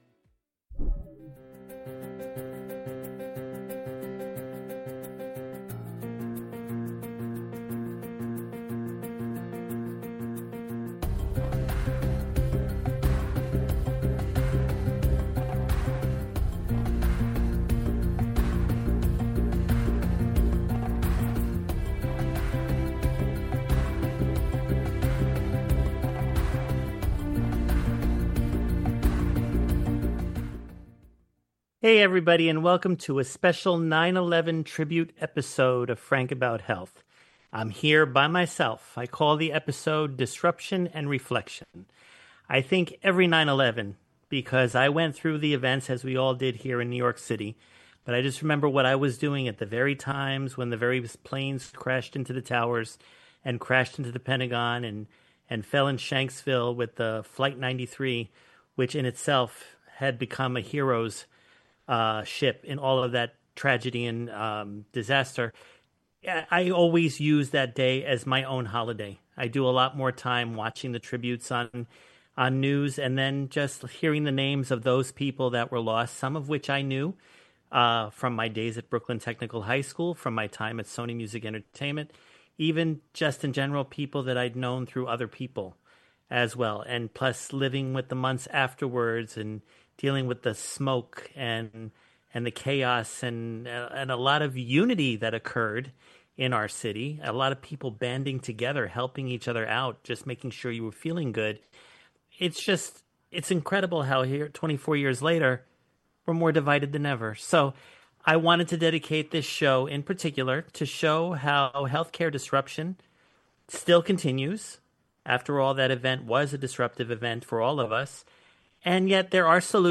It is the anniversary of 9/11 and while I won't pay direct tribute to the events of that day I will show in a solo episode the self-advocacy that we need to be aware of going forward with our health.